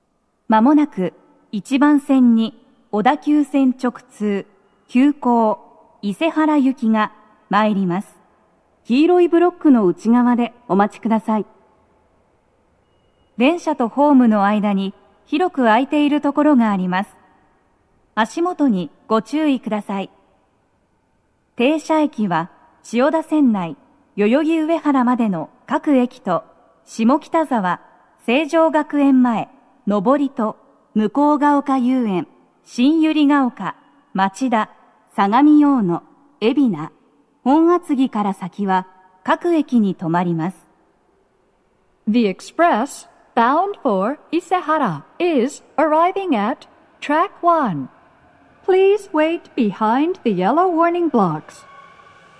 鳴動は、やや遅めです。鳴動中に入線してくる場合もあります。
接近放送8